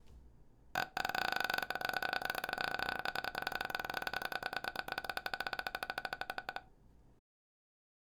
最後も同じく最低限のパワーの「あ」でじりじり音を作っていきましょう。息を出すか出さないかのラインで音が鳴るポイント探すのがコツです。
※見本音声